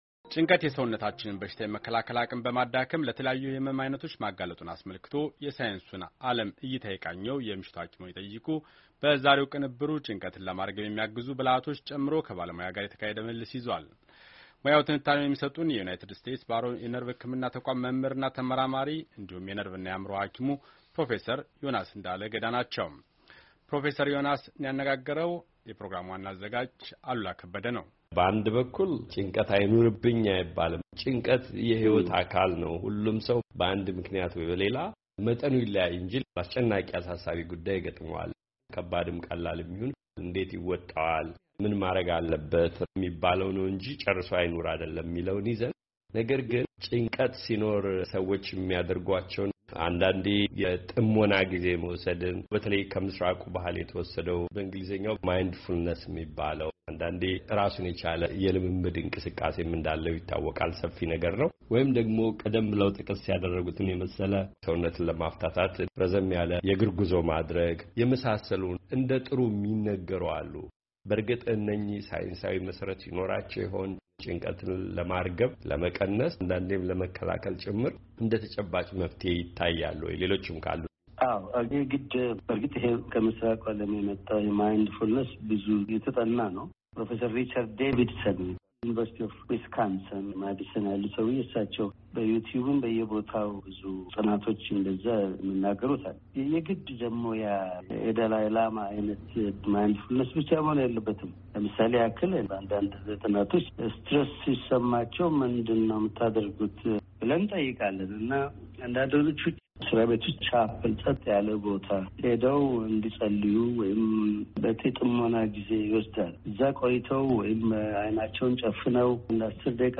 ጭንቀት የሰውነታችንን በሽታን የመከላከል አቅም በማዳከም ለተለያዩ የህመም ዐይነቶች ማጋለጡን አስመልክቶ የሳይንሱን ዓለም እይታ የቃኘው የምሽቱ ሃኪምዎን ይጠይቁ በተከታታይ ቅንብሩ ጭንቀትን ለማርገብ የሚያግዙ ብልሃቶች ጨምሮ ከባለ ሞያ ጋር የተካሄደ ምልልስ ይዟል።